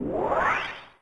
shuffle.wav